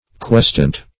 Search Result for " questant" : The Collaborative International Dictionary of English v.0.48: Questant \Quest"ant\, n. [OF. questant, F. qu[^e]tant, p. pr.]
questant.mp3